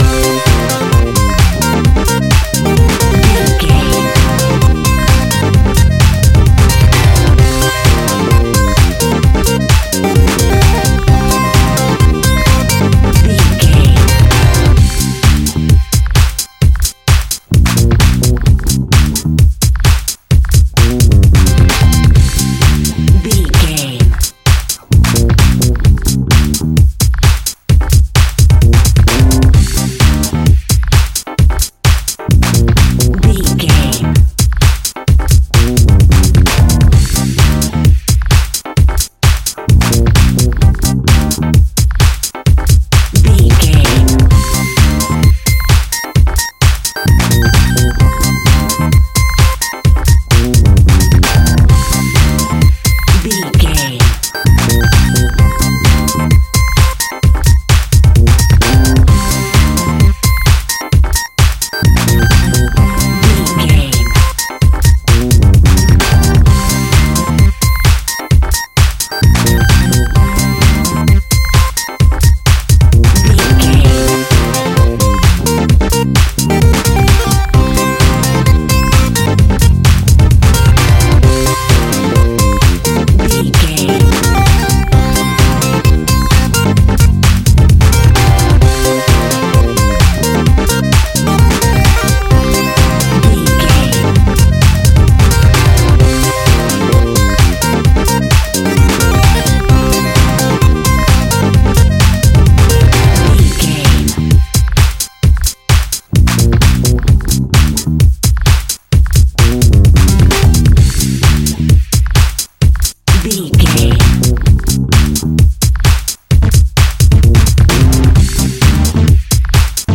Aeolian/Minor
groovy
uplifting
bouncy
smooth
drum machine
electric piano
bass guitar
synthesiser
house music
energetic
synth lead
synth bass